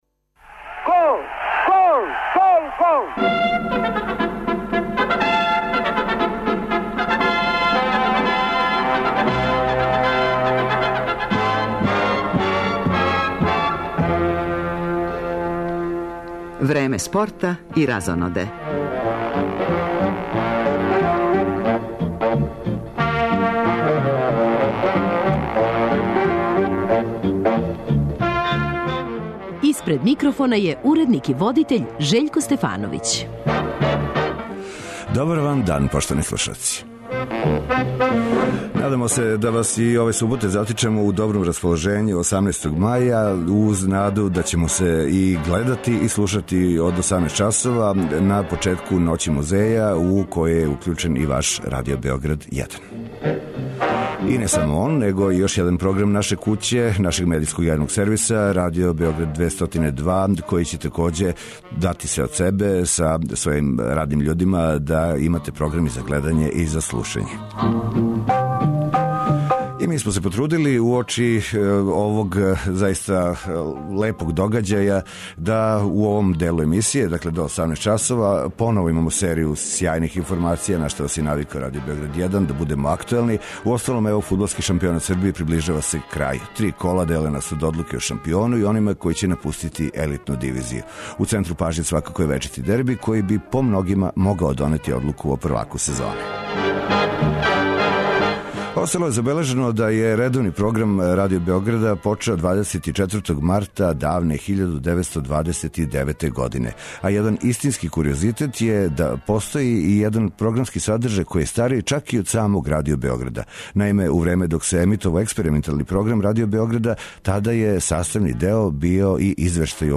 Од 18 часова и ова емисија,као и Радио Београд 1, придружују се Ноћи музеја.